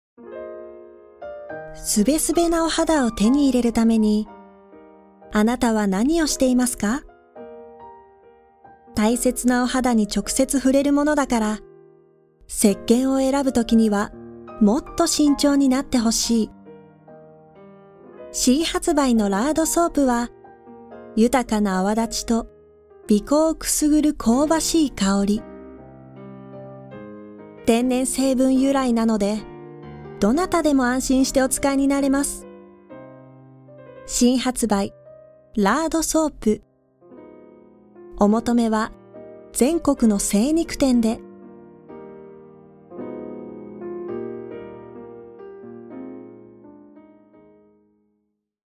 出⾝地・⽅⾔ 大阪府・関西弁
ボイスサンプル